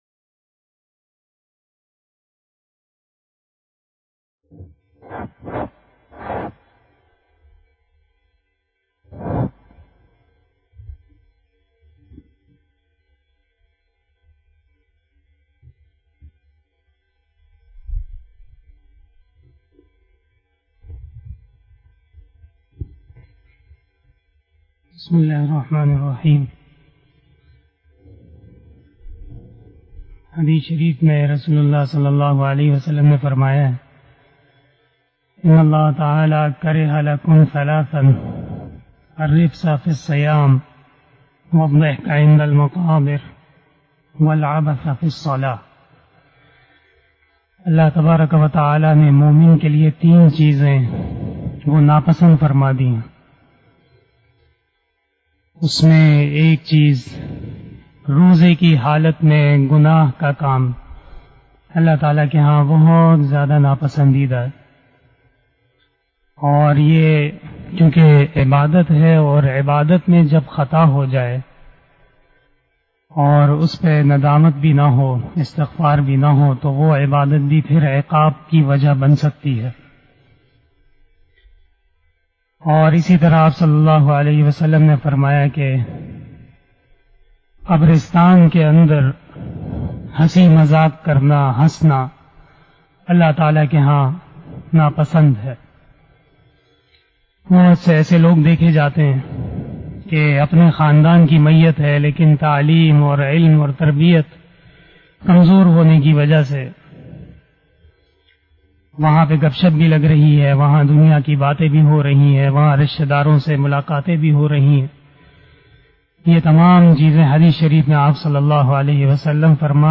031 After Isah Namaz Bayan 07 July 2021 (26 Zulqadah 1442HJ) Thursday